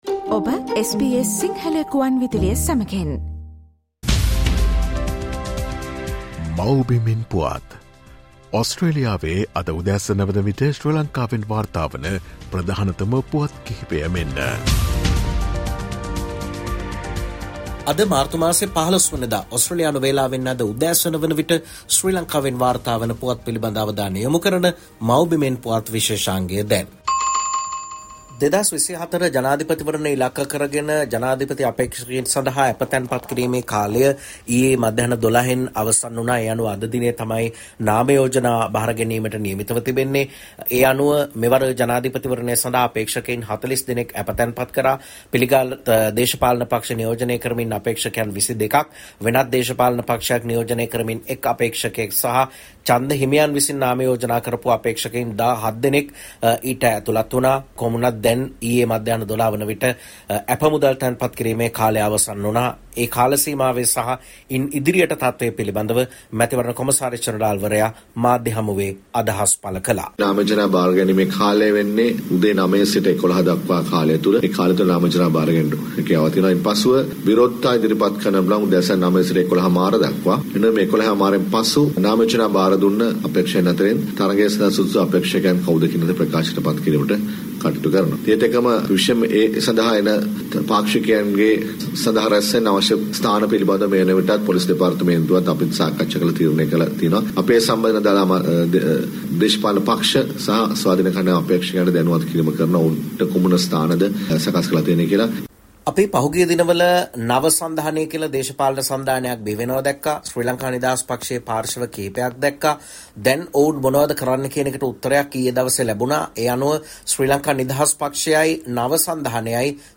"Homeland News" featuring the latest news from Sri Lanka.